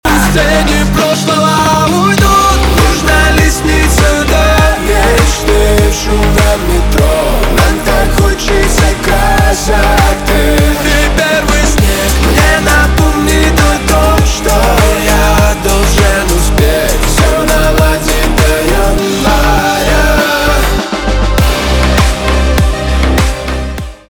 поп
битовые , басы , красивые , чувственные
грустные , качающие